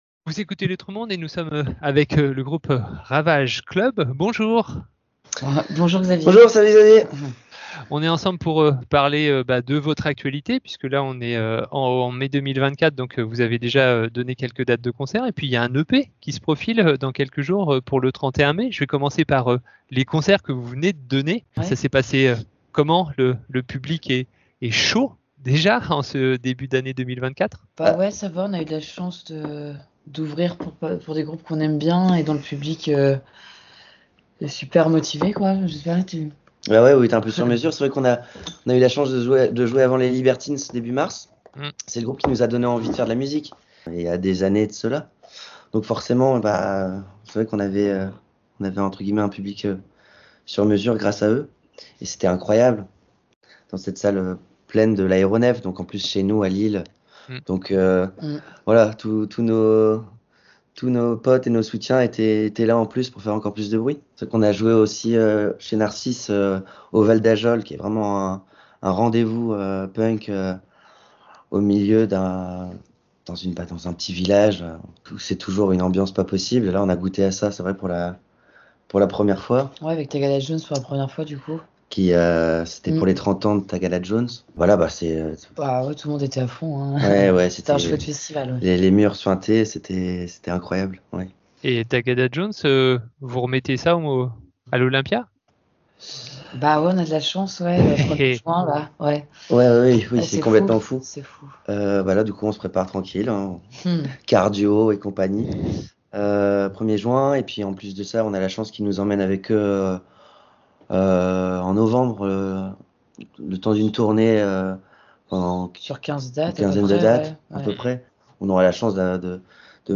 interview du groupe Ravage Club pour la sortie de leur album